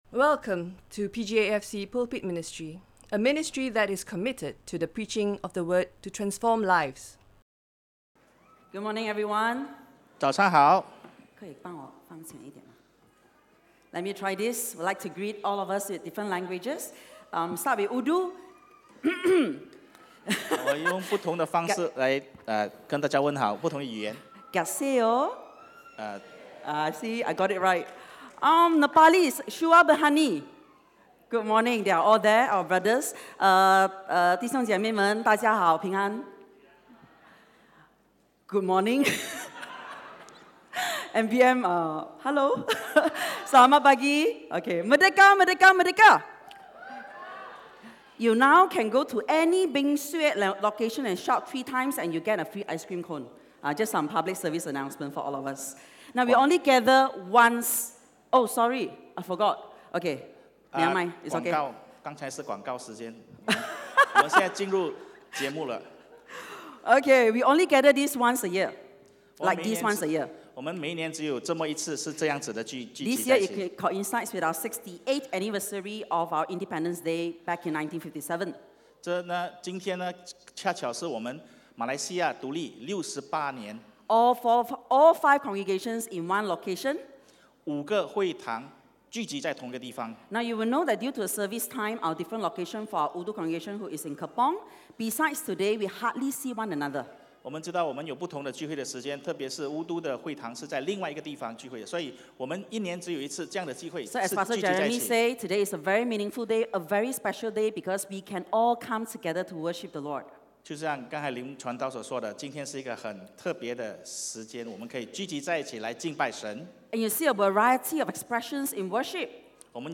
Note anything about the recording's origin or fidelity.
In conjunction with Merdeka Day and Combined Service, this is a stand alone sermon.